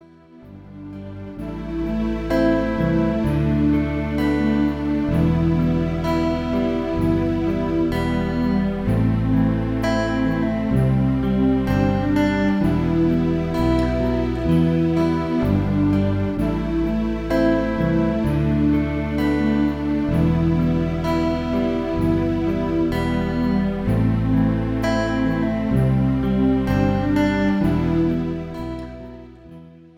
chants the sacred names of the seven earth spirits